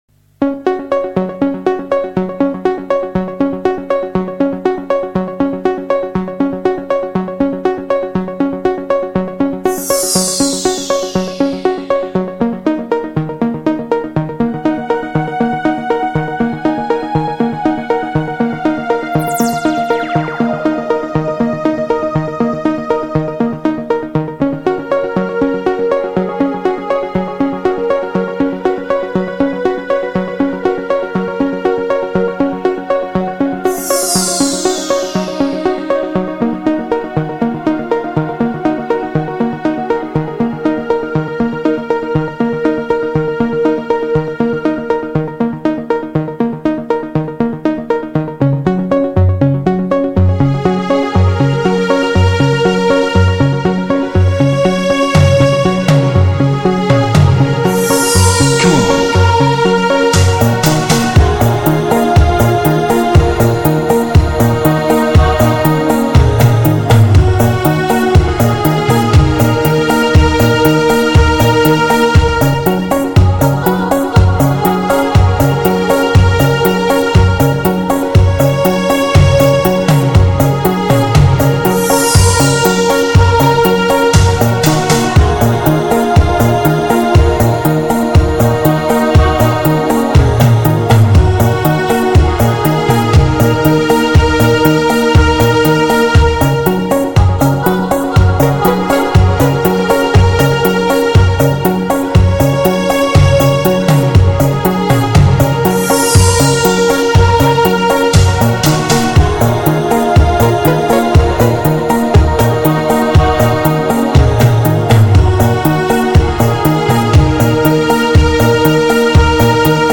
音乐类别：新世纪音乐 > 电音/融合/神秘
此张专辑可以说是电子乐的典范之作，前卫、动感、空灵、震撼的旋律令人如痴如醉！
为低音质MP3